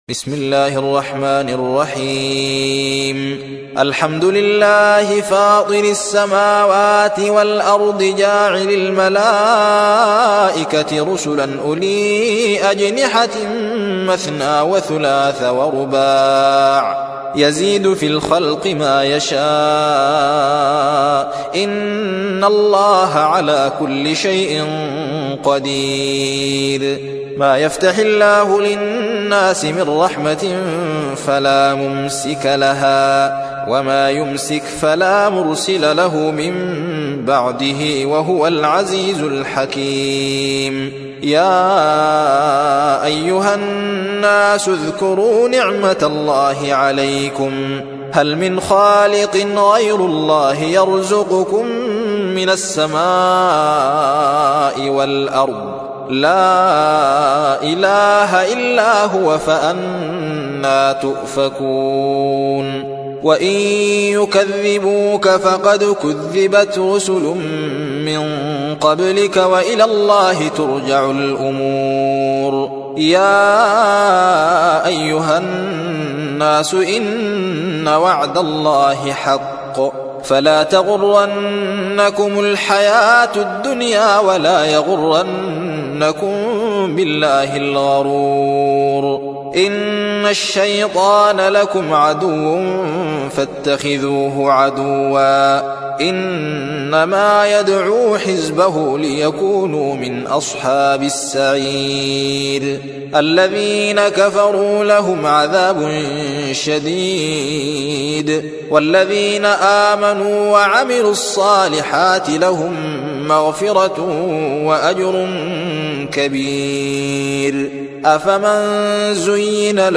35. سورة فاطر / القارئ